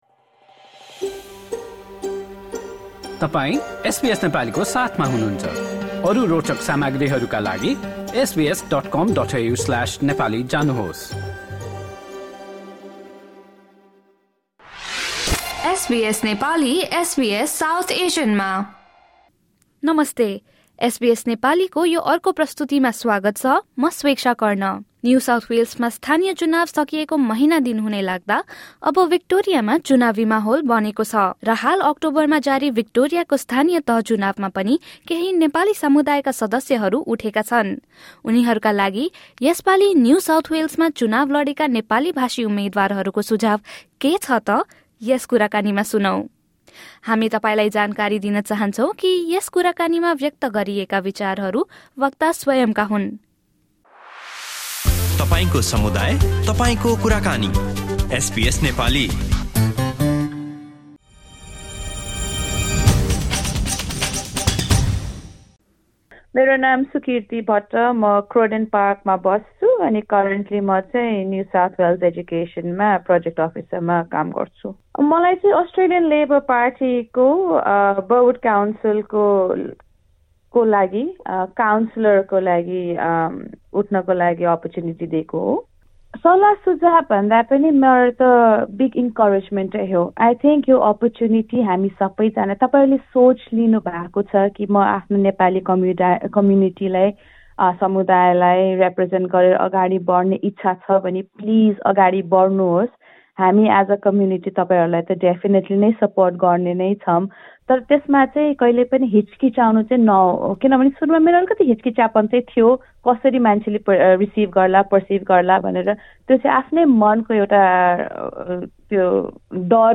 As the Victorian local elections approach wrap up, some Nepali-speaking candidates who contested in the NSW local elections in September share their perspectives on the Nepali community's engagement in Australian politics. Listen to their conversation with SBS Nepali.